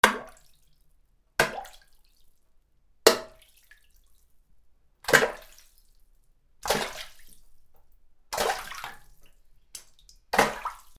/ M｜他分類 / L30 ｜水音-その他
水に手を叩きつける
『チュポン』